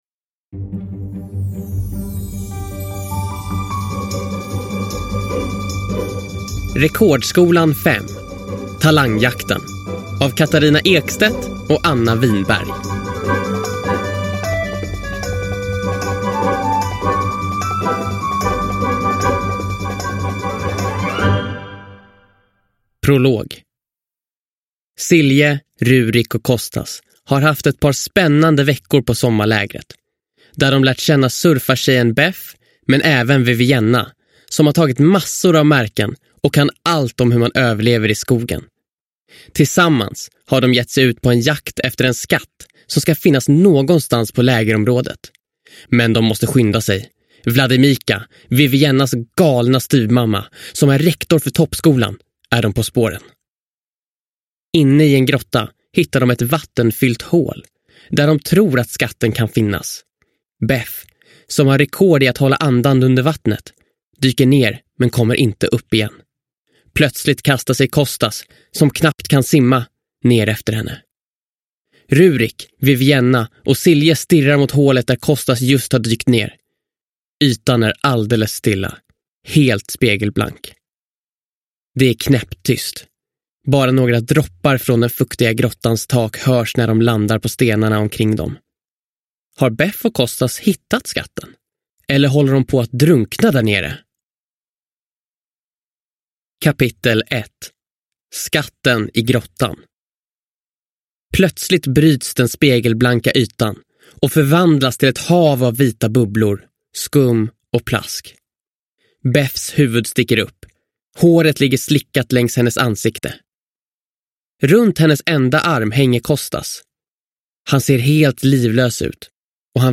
Rekordskolan 5: Talangjakten – Ljudbok – Laddas ner